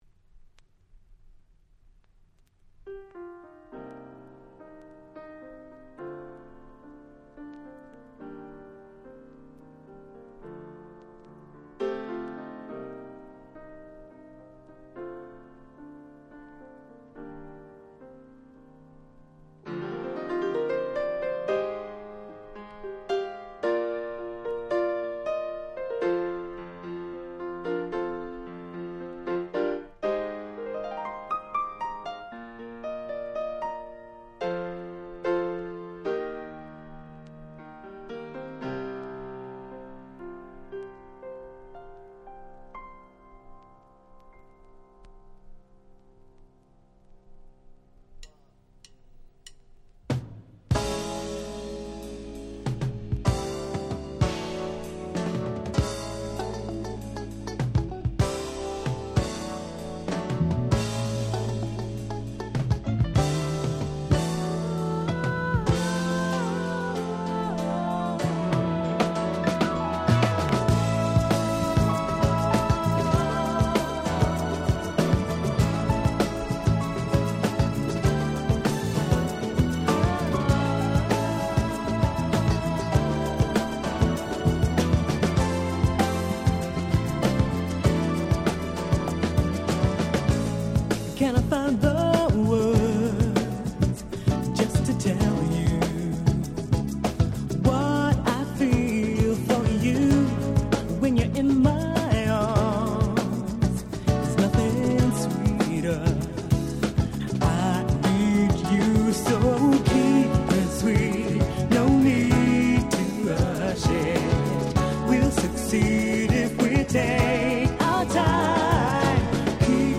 ※一部試聴ファイルは別の盤から録音してございます。